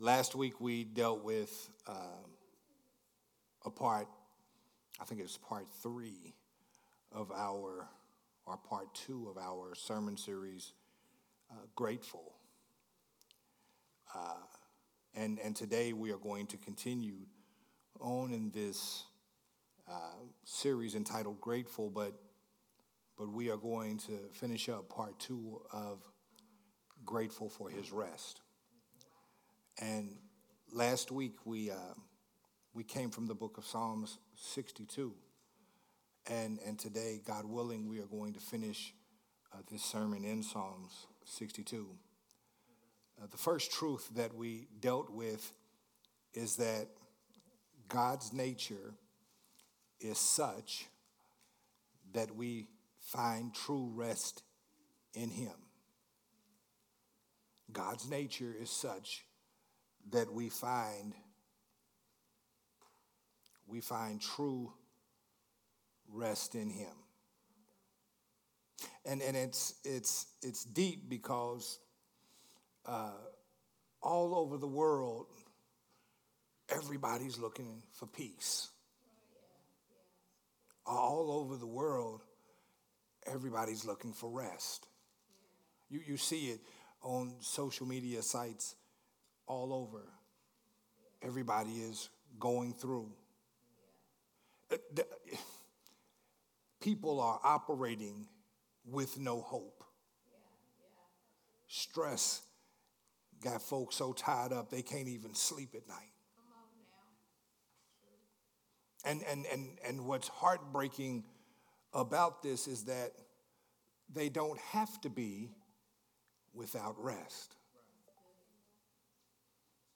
“Grateful for His Rest”, is the second part of Grateful, Part 2, recorded at Growth Temple Ministries on Sunday, November 16th, 2025.